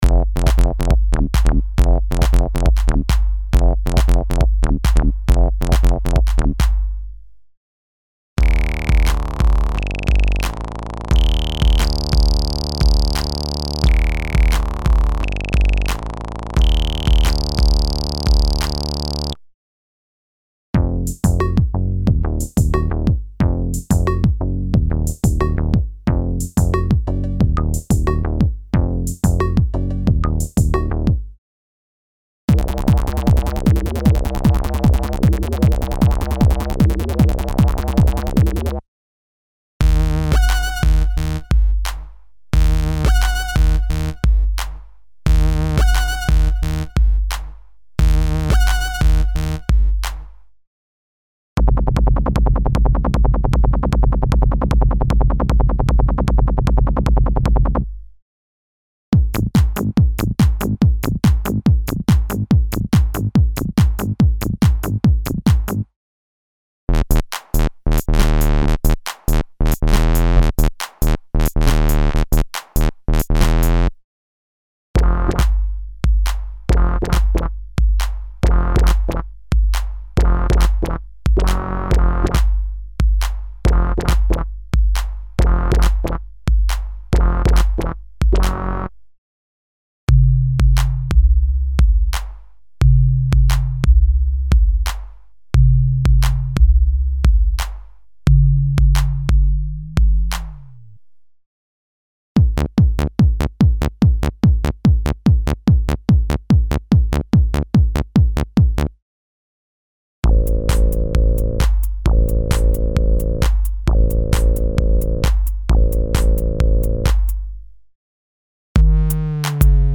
Original collection of analog & digital basses and leads with modern feeling for a wide variety of music styles (Techno, House, Trance, Jungle, Rave, Break Beat, Drum´n´Bass, Euro Dance, Hip-Hop, Trip-Hop, Ambient, EBM, Industrial, etc.).
Info: All original K:Works sound programs use internal Kurzweil K2661 ROM samples exclusively, there are no external samples used.